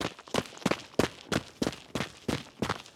SFX_Footsteps_Sand_Run_01.wav